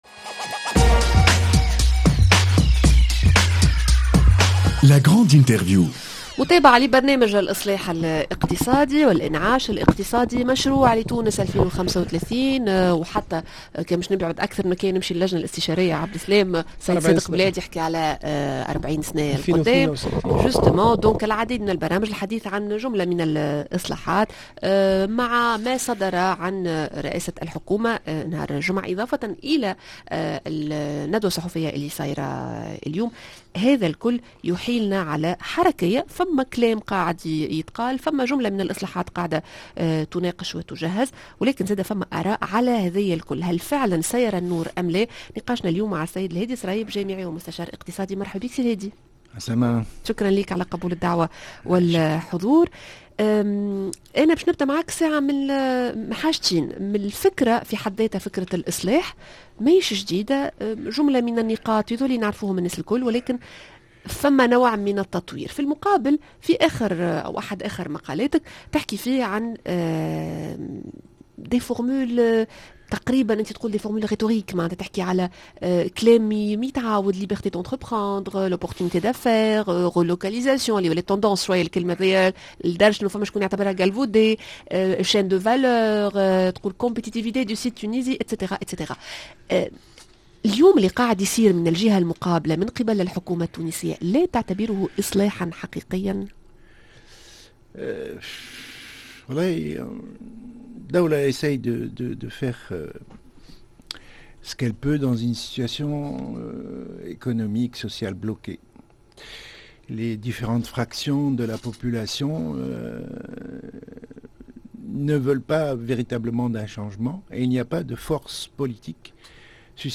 La grande interview: برنامج اصلاحات و انعاش اقتصادي و مشروع تونس 2035 ...